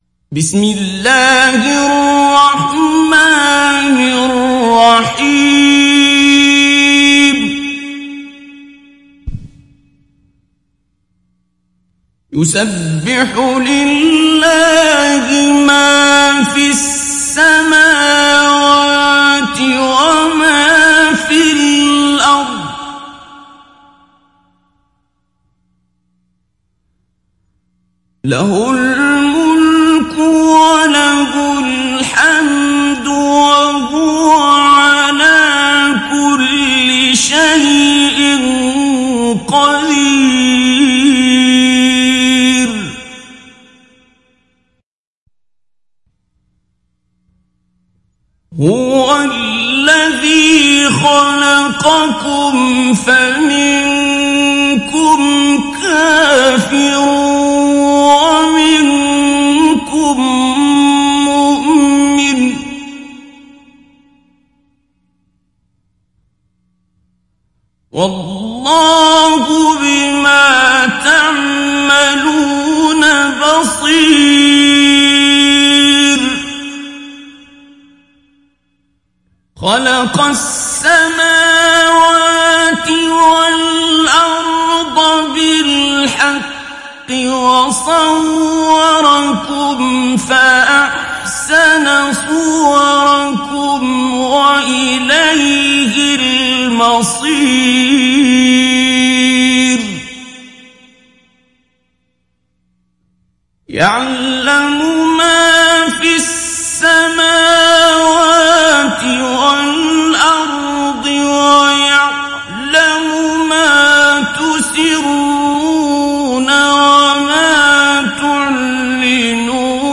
دانلود سوره التغابن mp3 عبد الباسط عبد الصمد مجود روایت حفص از عاصم, قرآن را دانلود کنید و گوش کن mp3 ، لینک مستقیم کامل
دانلود سوره التغابن عبد الباسط عبد الصمد مجود